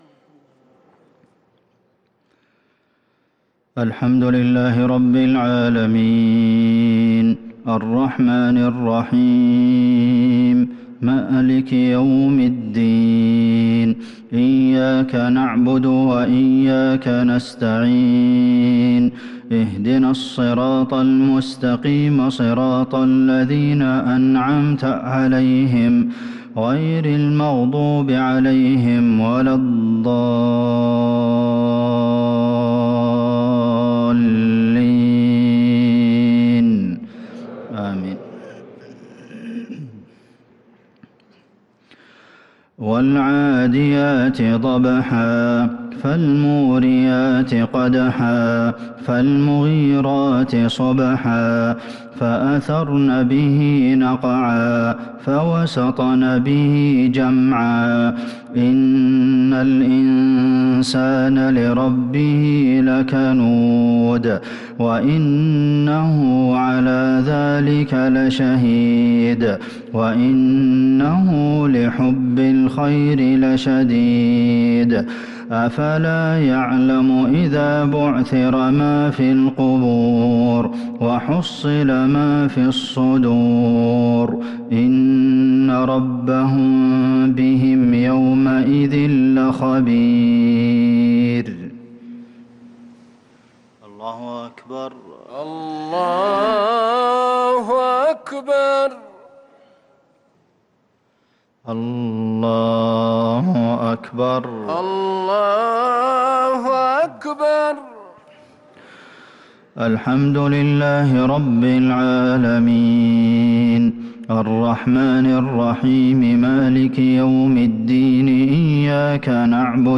مغرب الخميس 3-6-1443هـ سورتي العاديات و القارعة | Maghrib prayer Surah Al-'Adiyat and Al-Qari'ah 6-1-2022 > 1443 🕌 > الفروض - تلاوات الحرمين